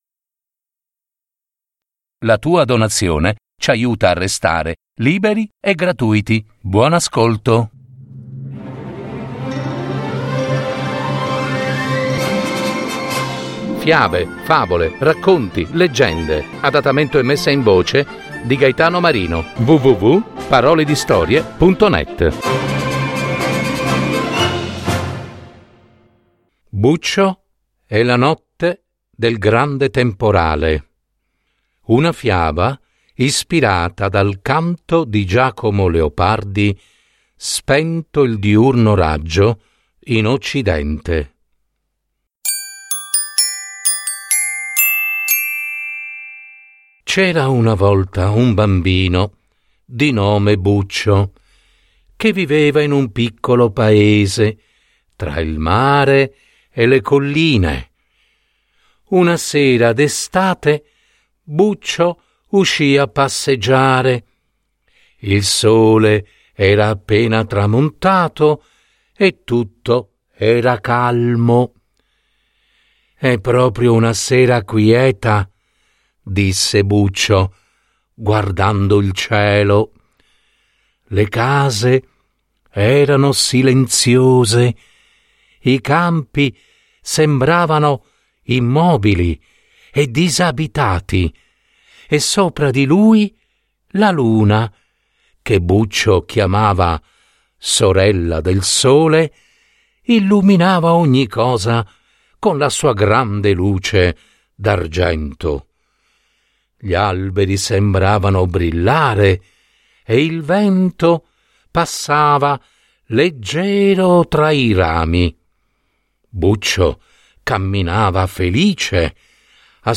Genres: Kids & Family, Stories for Kids